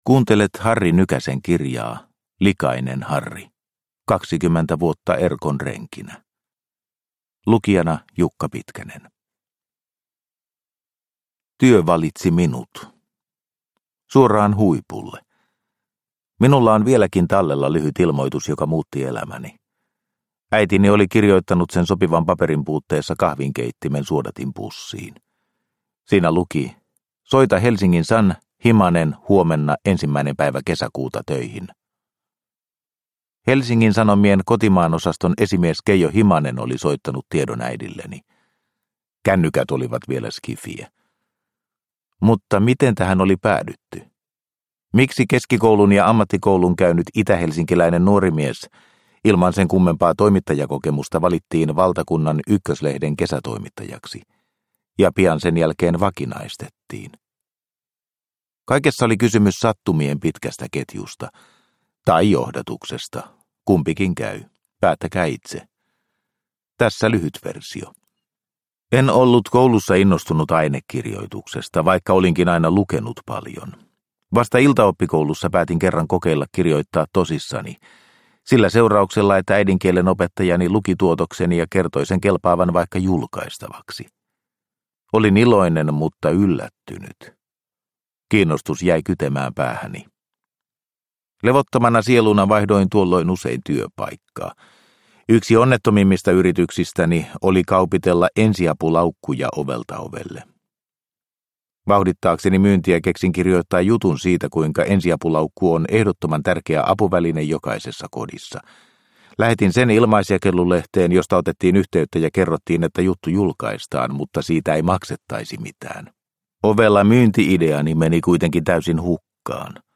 Likainen Harri – Ljudbok – Laddas ner